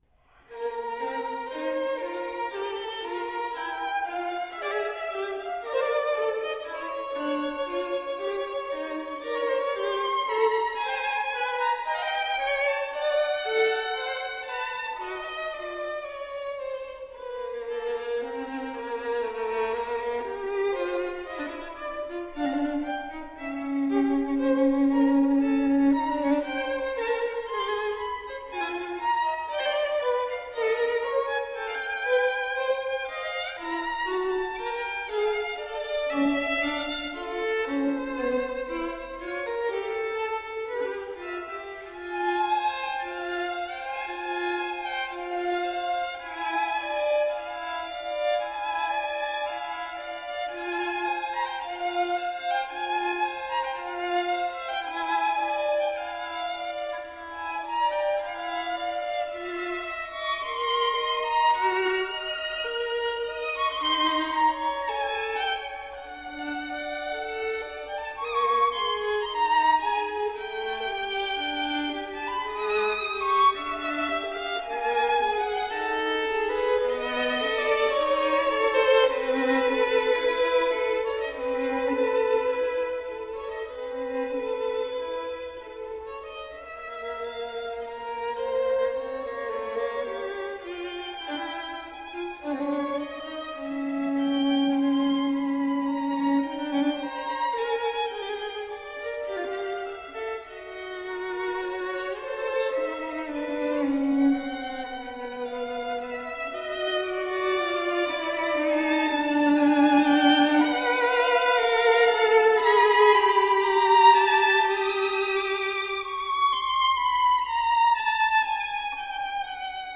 Violine
Verzierungen II (Mordent, Doppelschlag)